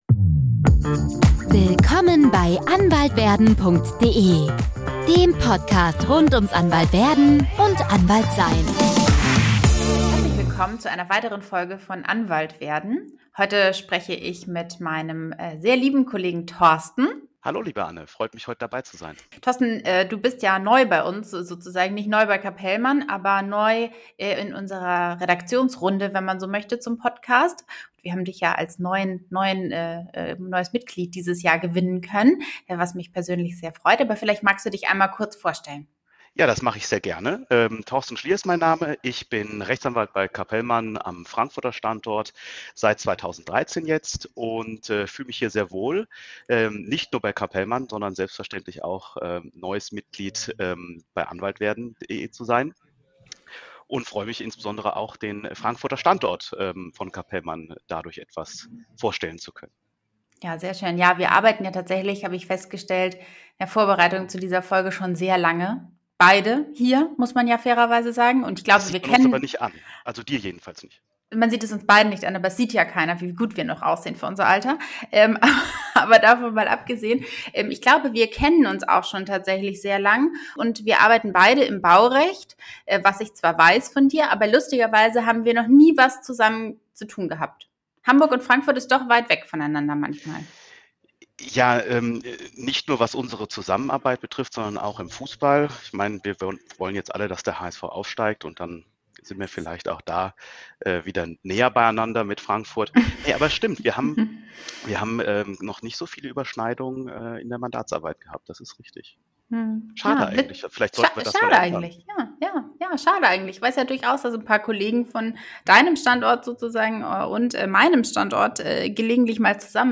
Ein ehrliches Gespräch über Umwege, Schwerpunktentscheidungen, die ersten Schritte im Beruf und die Frage, was einen guten Einstieg ins Berufsleben ausmacht.